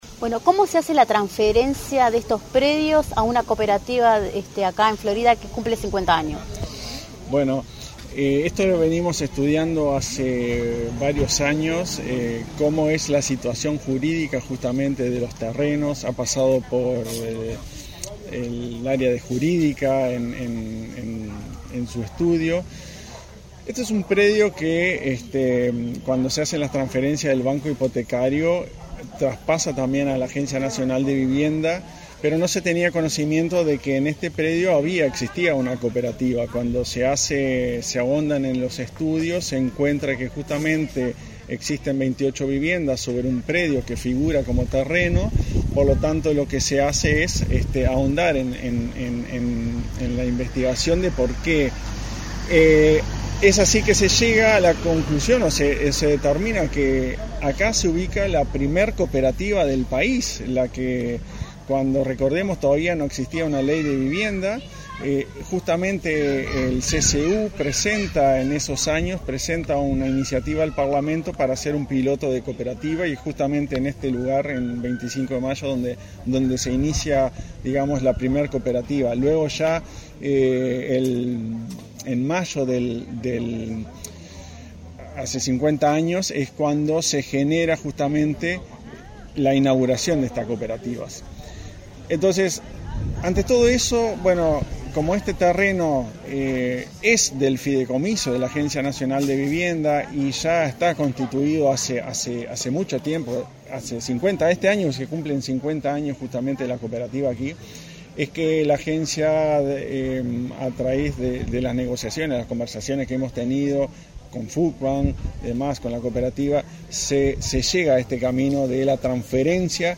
Entrevista al director de la ANV, Klaus Mill